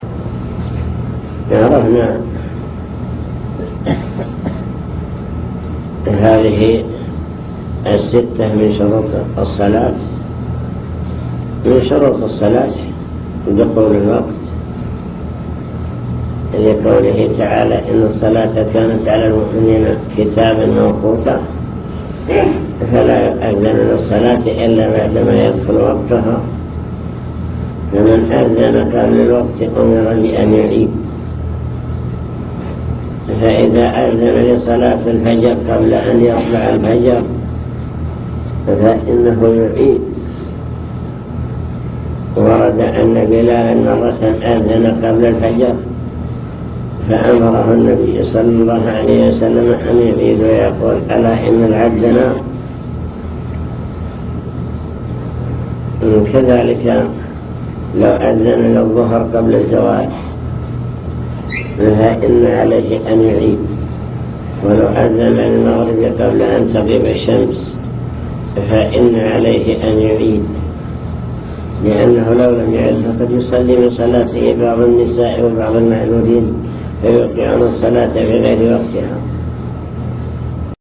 المكتبة الصوتية  تسجيلات - محاضرات ودروس  درس الفجر